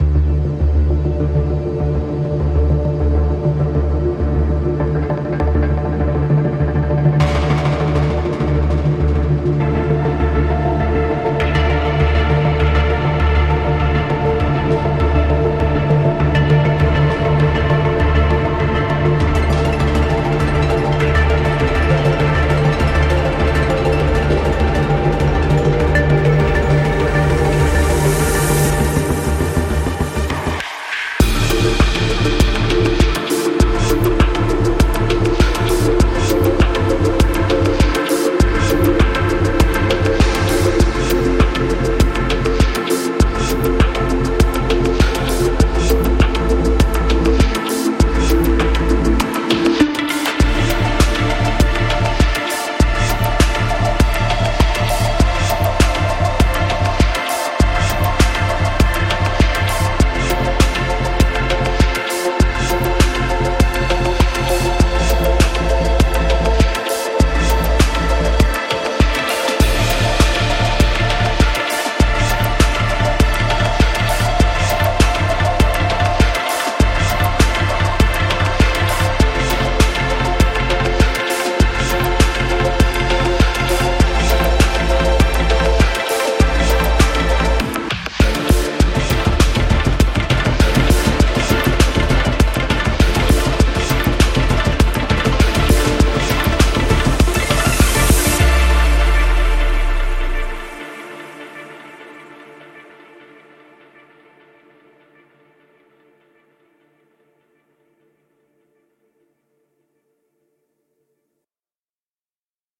Elysium是Wide Blue Sounds的最新旗舰合成器，可在Kontakt乐器中提供打击乐和合成分层，并承诺可以创造出任何竞争者都无法复制的声音。
你会在打击乐层中找到大量的虚拟音色，包括：合成器、鼓锤、钢琴、吉他等，每种音色都很容易变形。